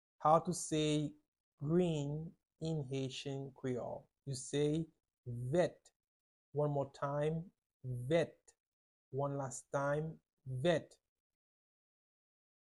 Listen to and watch “vèt” audio pronunciation in Haitian Creole by a native Haitian  in the video below:
10.How-to-say-Green-in-Haitian-Creole-–-vet-with-Pronunciation.mp3